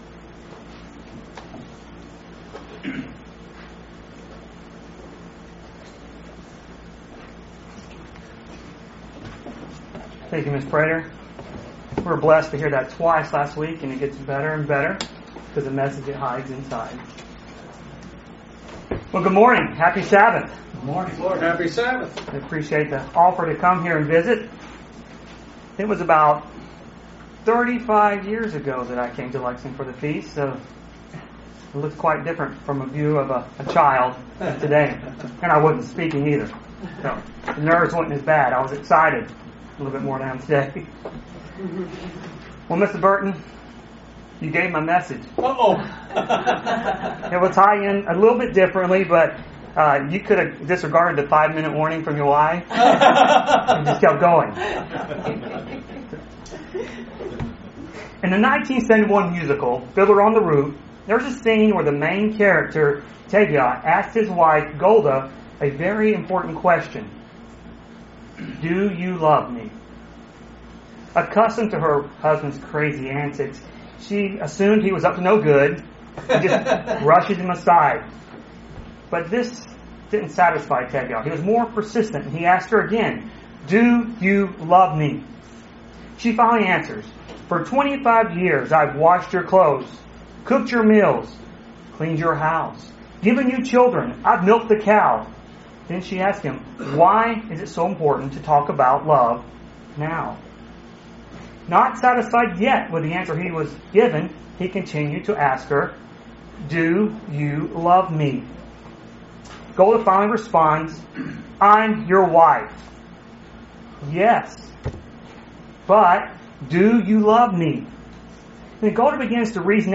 Sermons
Given in Lexington, KY